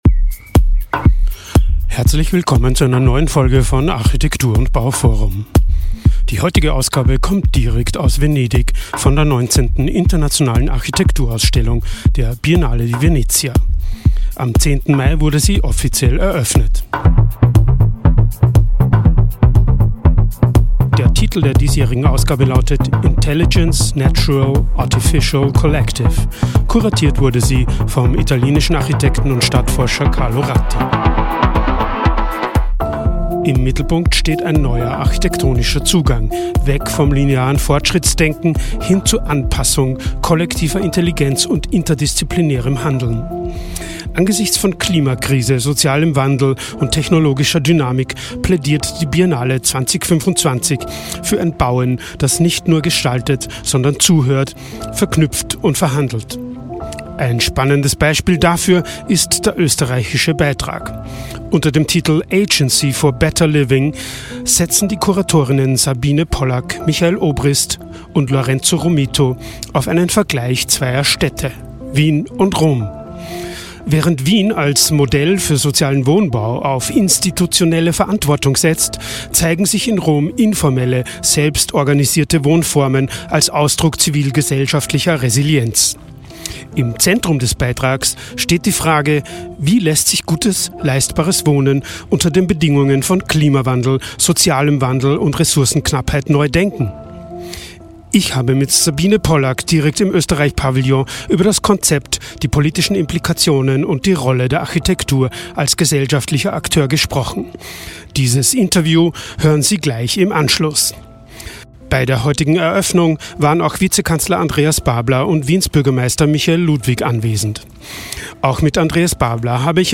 Im Podcast spricht sie über Wohnungskrisen, Bodenpolitik, gemeinschaftliches Wohnen und darüber, was Wien von Roms lebendiger Zivilgesellschaft lernen kann. Und: Auch der zuständige Minister, Vizekanzler Andreas Babler, kommt in dieser Folge zu Wort.